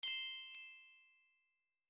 جلوه های صوتی
دانلود صدای اعلان خطر 7 از ساعد نیوز با لینک مستقیم و کیفیت بالا